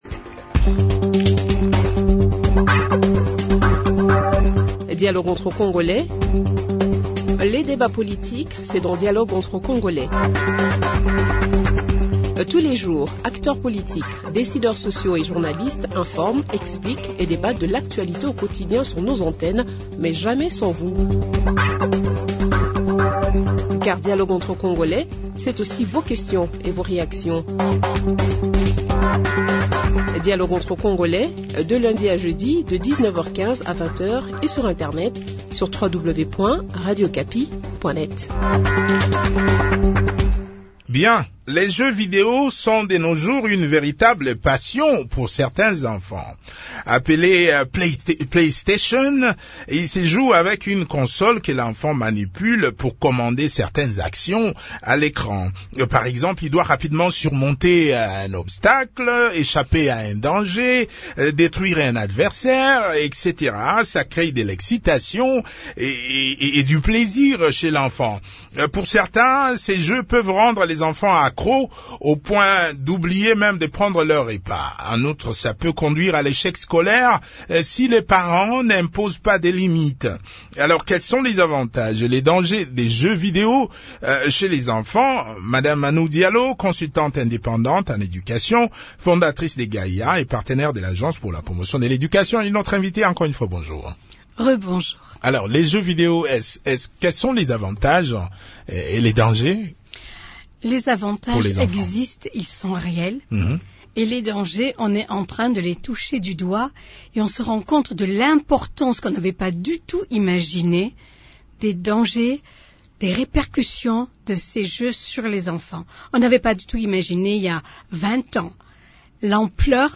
Quels sont les avantages et les inconvénients des jeux vidéo chez les enfants ? Les éléments de réponse dans cet entretien